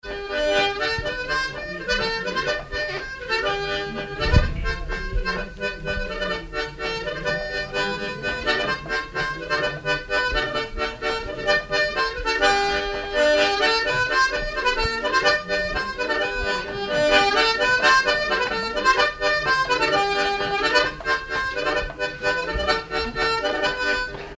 Polka
Bellevaux ( Plus d'informations sur Wikipedia ) Haute-Savoie
danse : polka
circonstance : bal, dancerie
Pièce musicale inédite